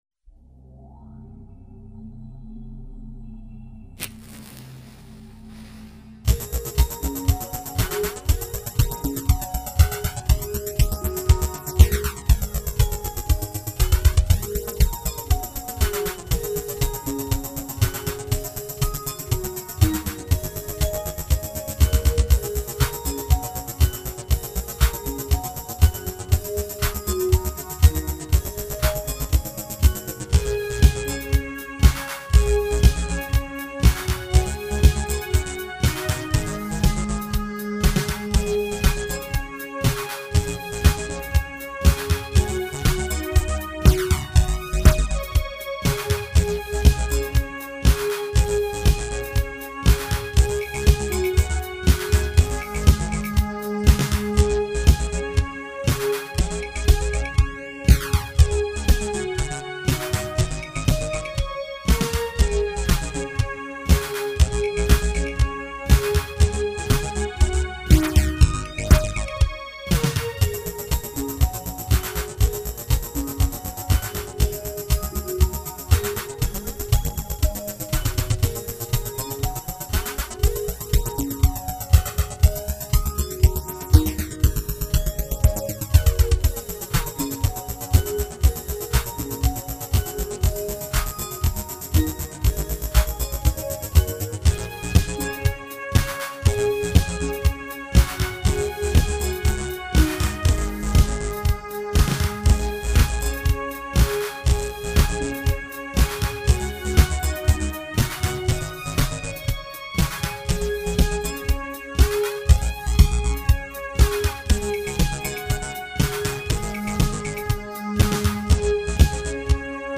File under: Electro Wave